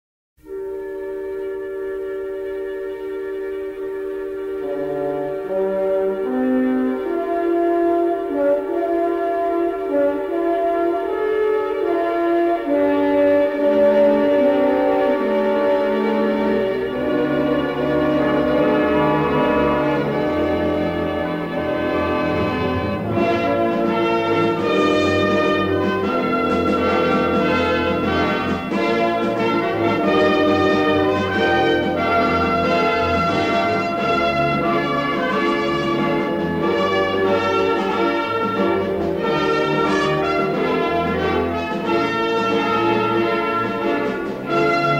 remastered from the original 1/4" stereo album tapes